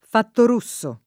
Fattorusso [ fattor 2SS o ] cogn.